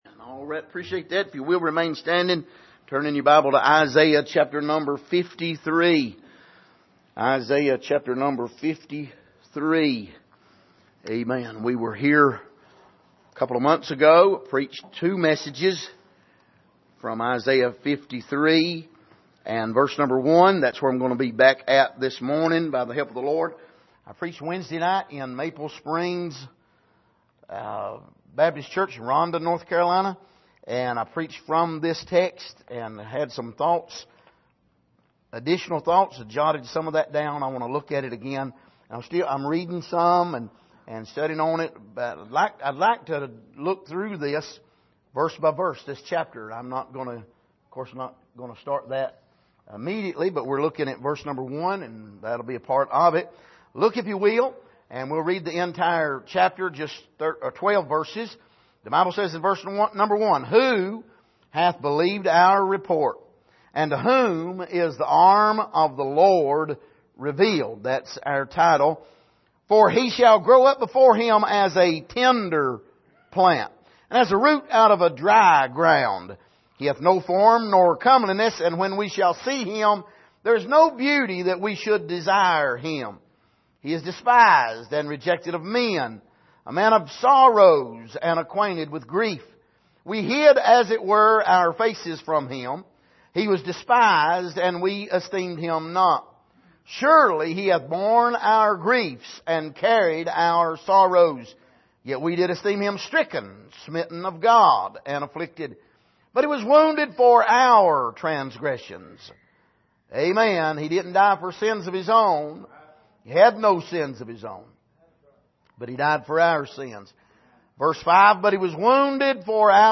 Passage: Isaiah 53:1-12 Service: Sunday Morning